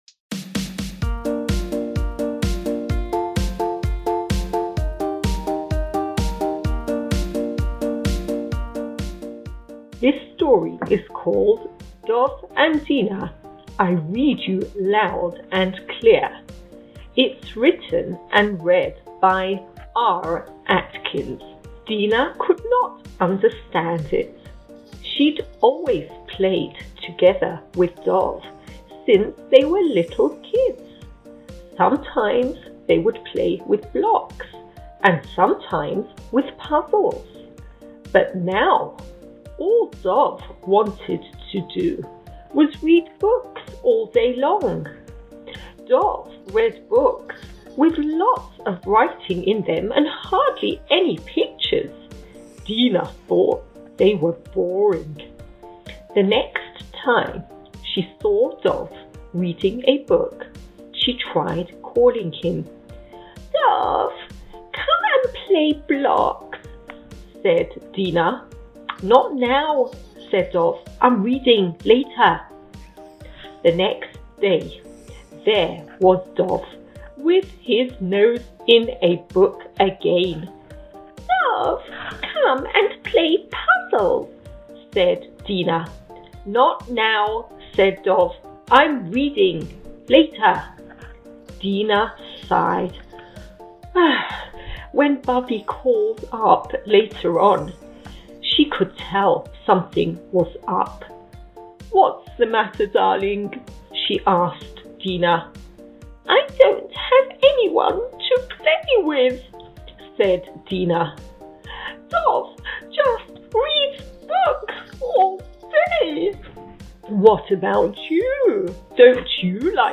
| Read-Along Storytime |